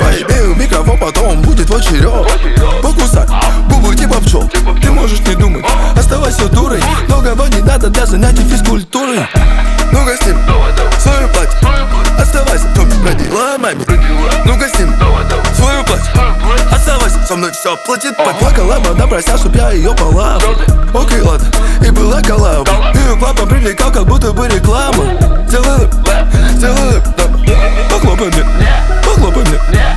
Hip-Hop Rap